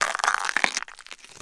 ALIEN_Insect_16_mono.wav